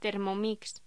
Locución: Termomix
voz
Sonidos: Hostelería